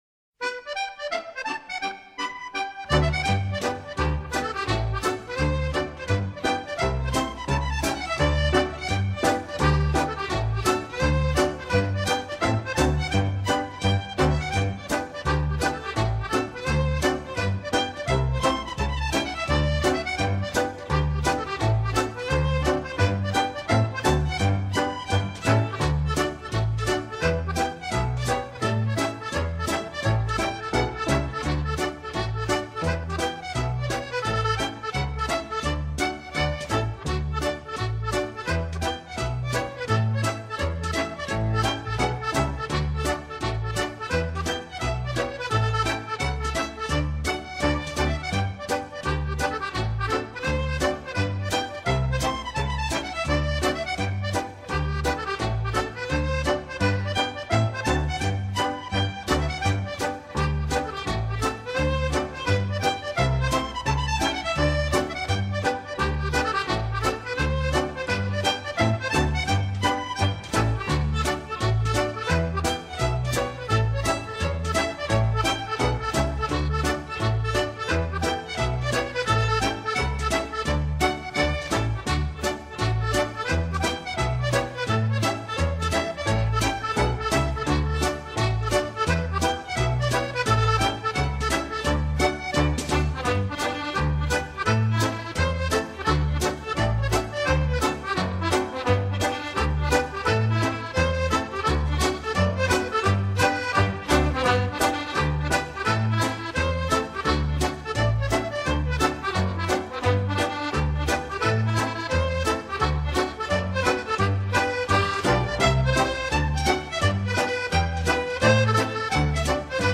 Genre: Traditional American.